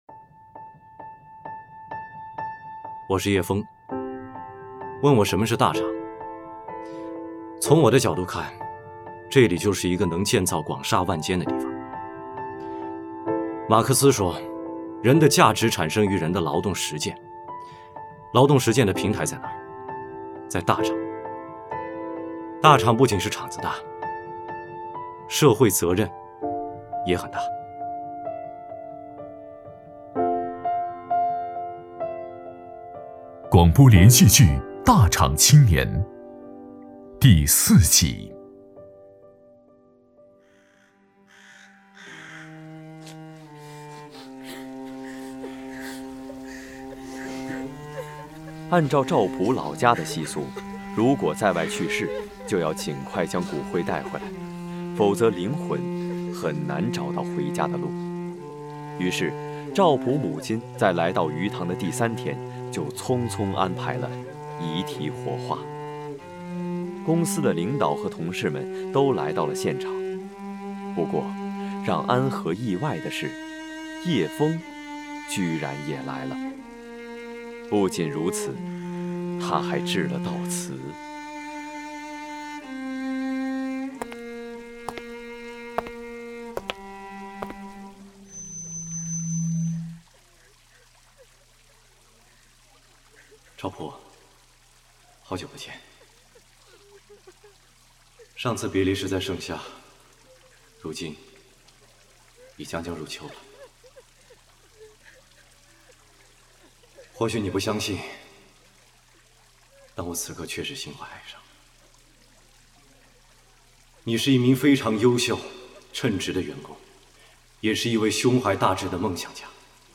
广播类型：连续剧